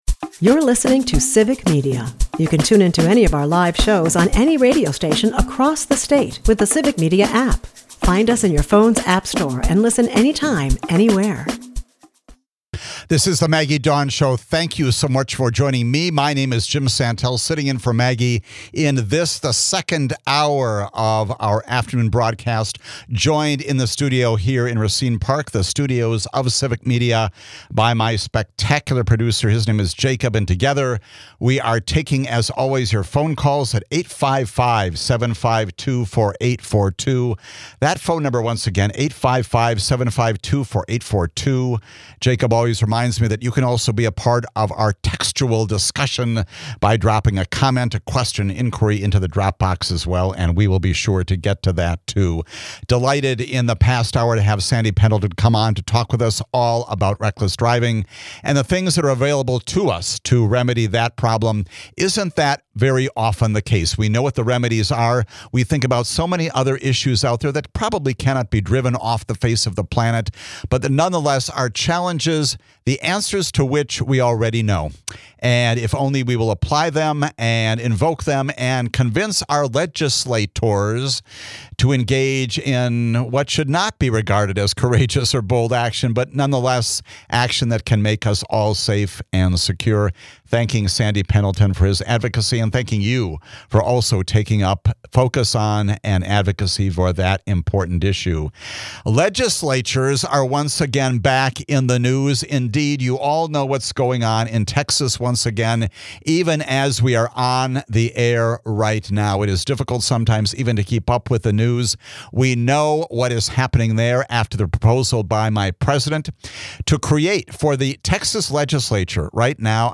Guest host Jim Santelle tackles the contentious Texas redistricting saga, where a GOP push to redraw congressional maps aims to secure a House majority. The episode dives into the historical and current implications of gerrymandering and Voting Rights Act challenges, spotlighting state-level quorum-breaking tactics.